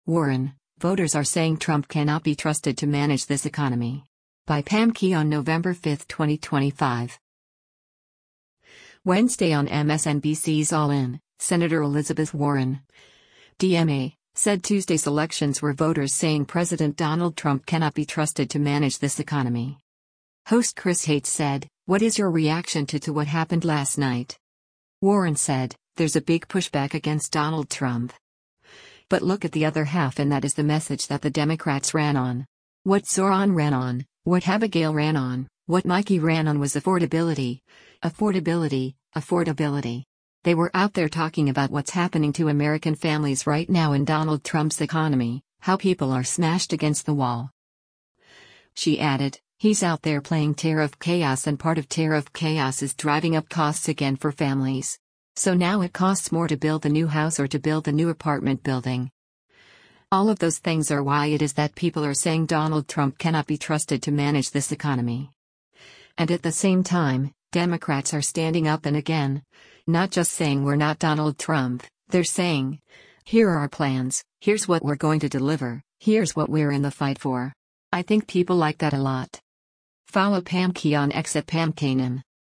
Wednesday on MSNBC’s “All In,” Sen. Elizabeth Warren (D-MA) said Tuesday’s elections were voters saying President Donald Trump “cannot be trusted to manage this economy.”
Host Chris Hates said, “What is your reaction to to what happened last night?”